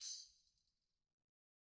Tamb1-Hit_v1_rr2_Sum.wav